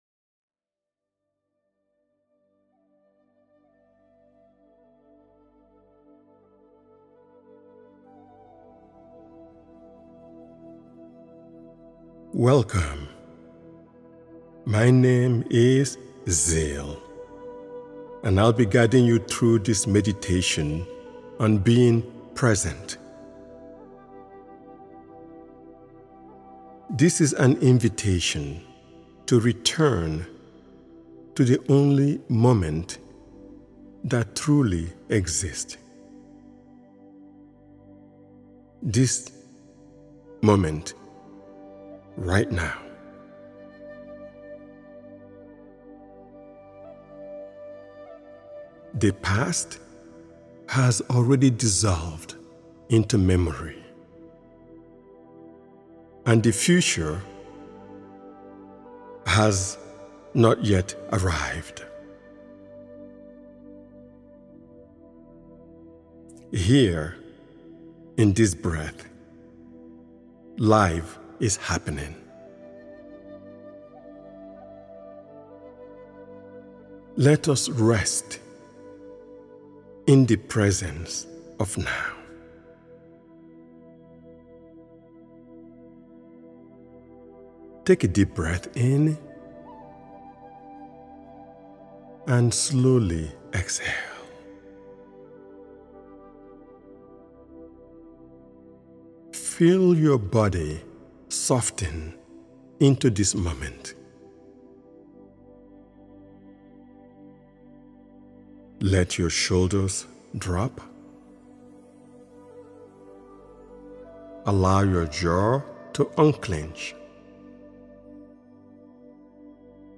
The Power of Now: Awaken to the Present Moment is a calming 5-minute guided meditation designed to help you step fully into the here and now.